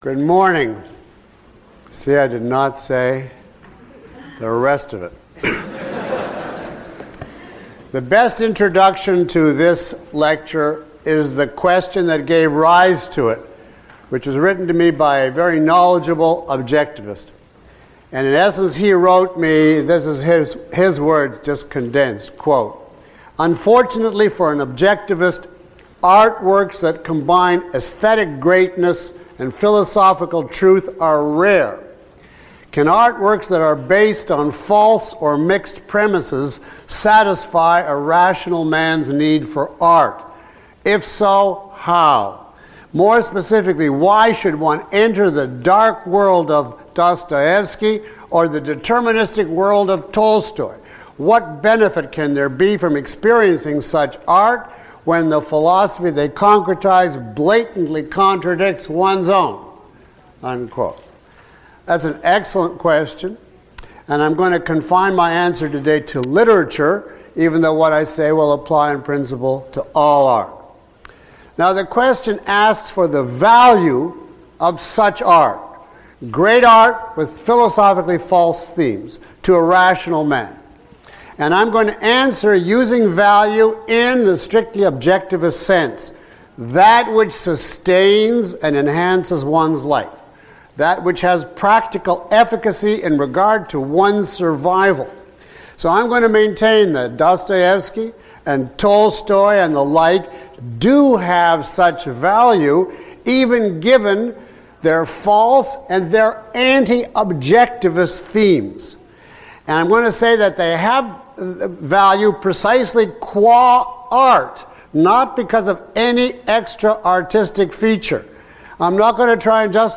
Lecture (MP3) Questions about this audio?